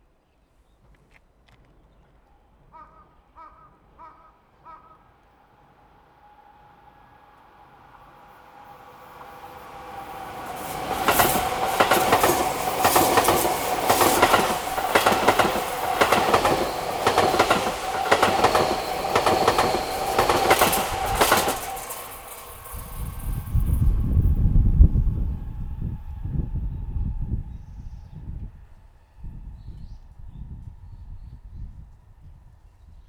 「清瀬カーブ」で、ZOOM Am7 Android用MSステレオマイクのテスト
上り電車通過。
HZOOM Am7 マイク指向性120＋付属ウインドスクリーン